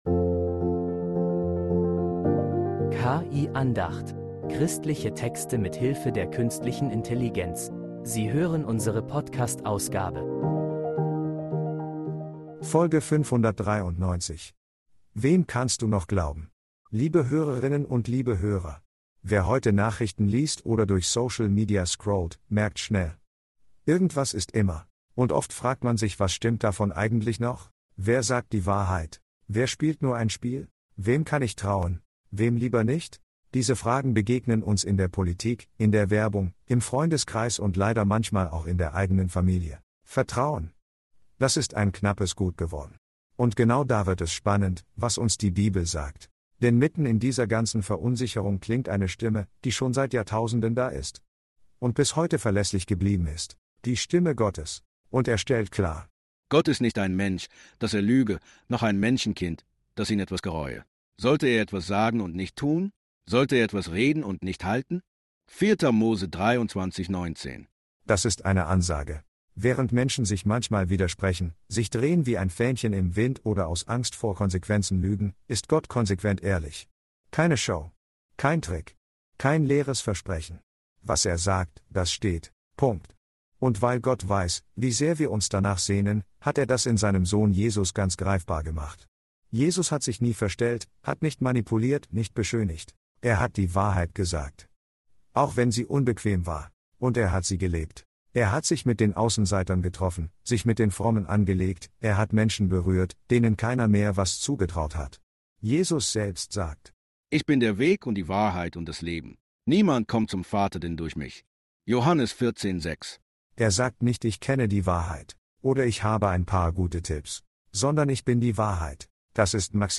Religion & Spiritualität